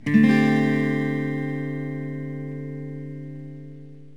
D7sus4.mp3